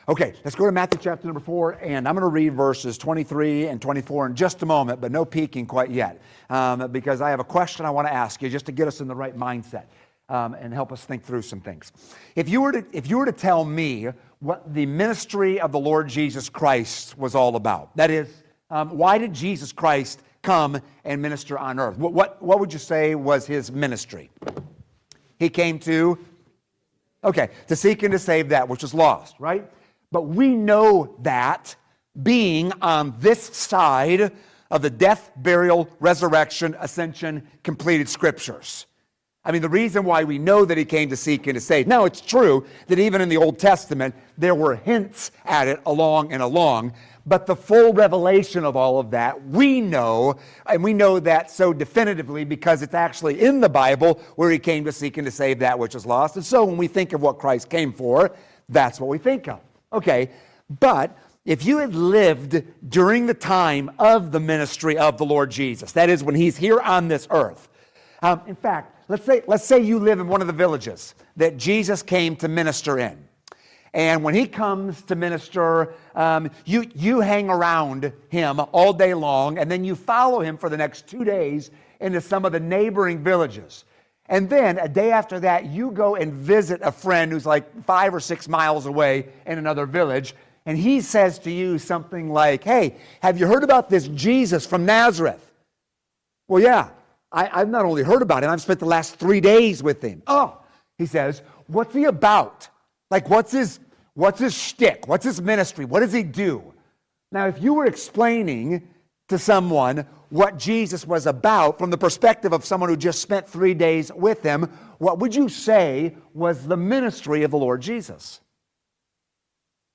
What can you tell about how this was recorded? Series: 2026 Spring Revival Matt. 4:23-24 Service Type: Midweek Service « What is Revival?